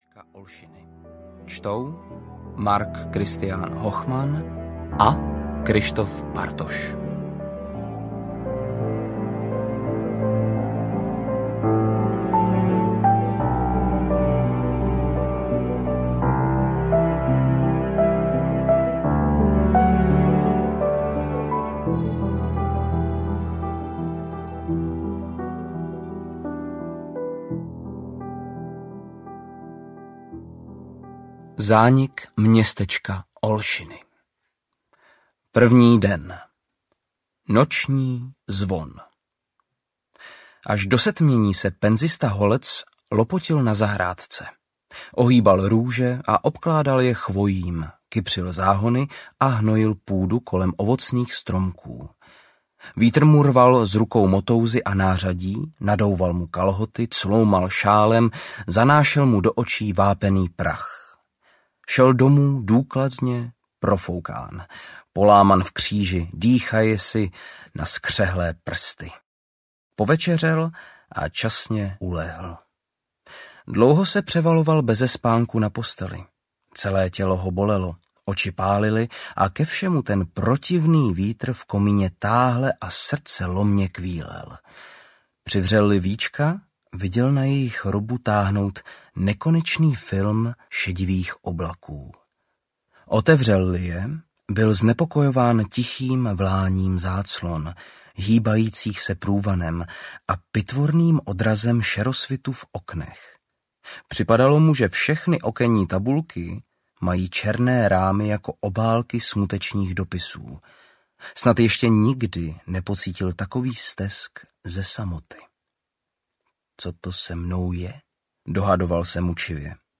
Zánik městečka Olšiny audiokniha
Ukázka z knihy